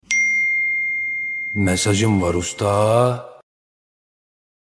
UYANAMAYANLAR için Alarm sesi Memati Zil Sesi
Kategori: Zil Sesleri
Açıklama: Ücretsiz olarak indirilebilen bu ses dosyası, Memati’nin ünlü "Mesajım Var Usta" repliğiyle telefonunuza gelen her mesajı özel kılacak.